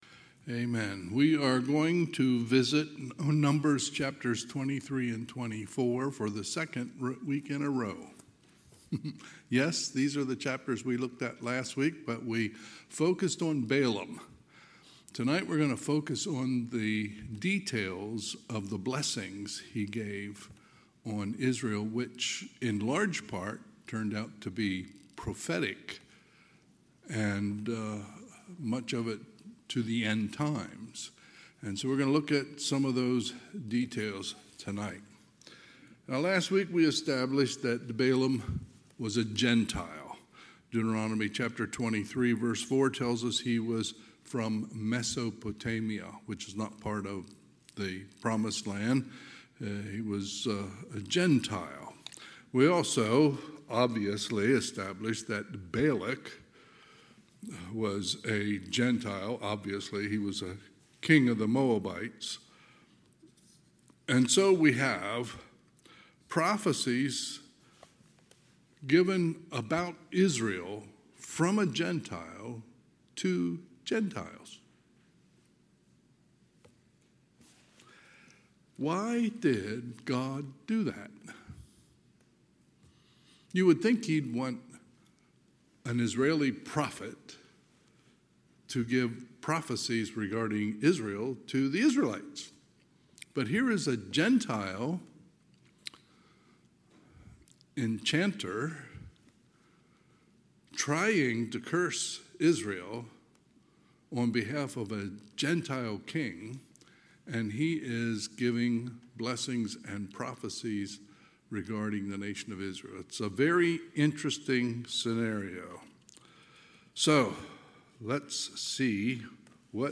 Sunday, July 20, 2025 – Sunday PM